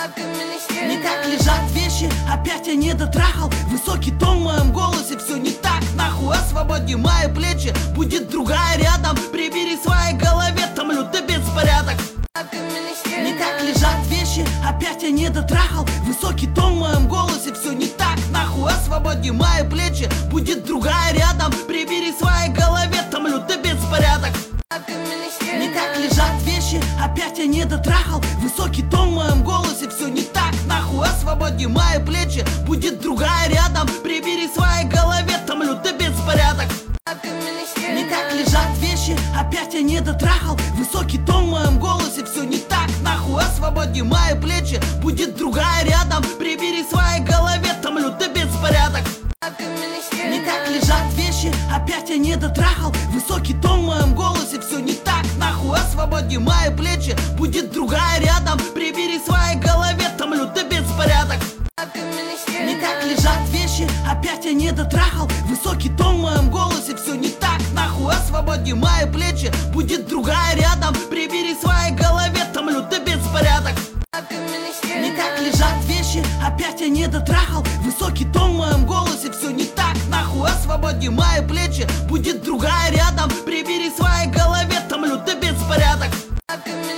Мужская версия